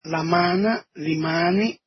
Vegetai | Dialetto di Albosaggia